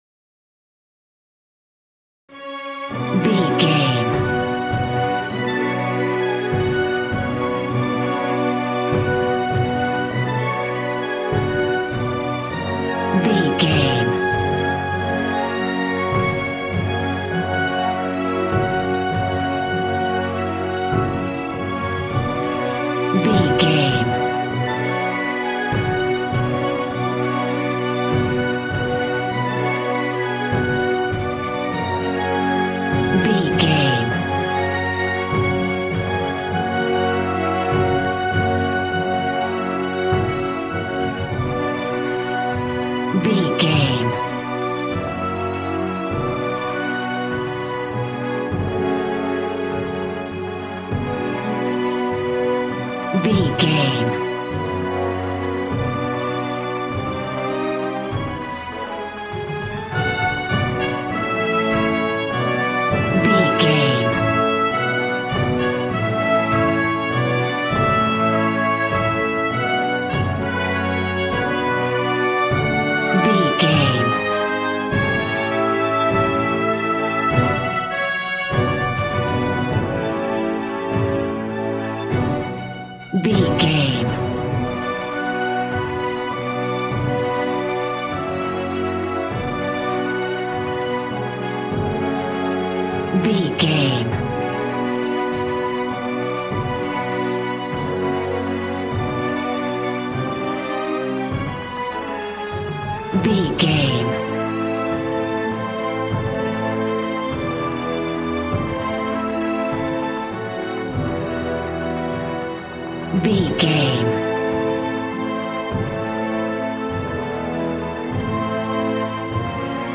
Aeolian/Minor
G♭
brass
strings
violin
regal